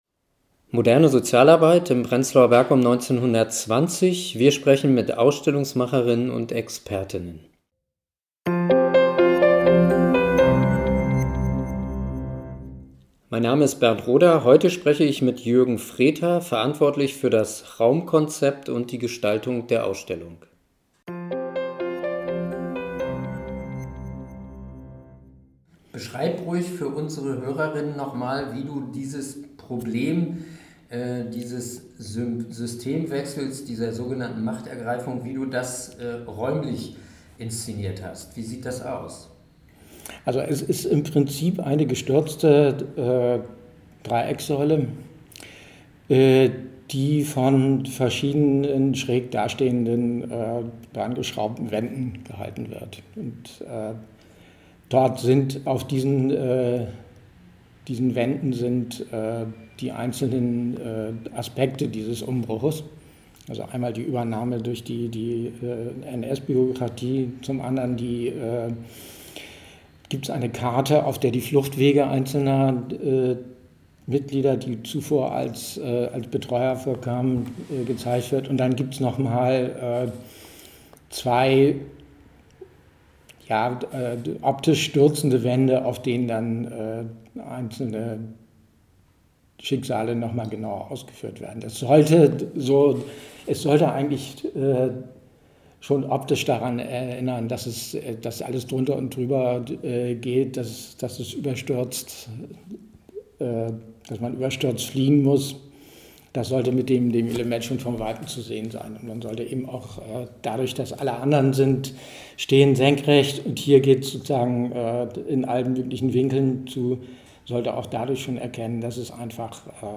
Interviews zum Thema: Aufbruch und Reformen – Pionierinnen und Pioniere der modernen Sozialarbeit in Prenzlauer Berg während der Weimarer Republik | Teil 2
Moderne Sozialarbeit in Prenzlauer Berg um 1920 – wir sprechen mit Ausstellungsmacher_innen und Expertinnen und Experten!